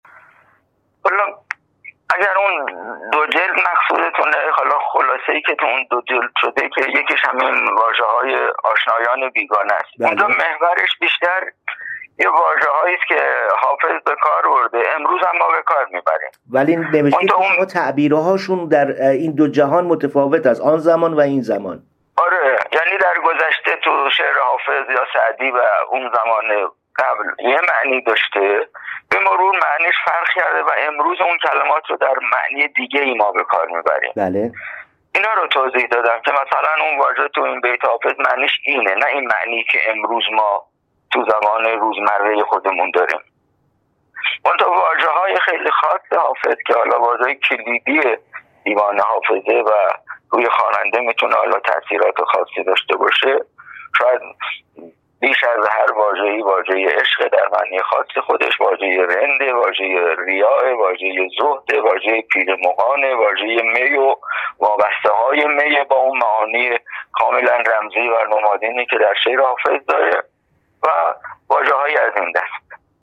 بربلندای امواج تلفن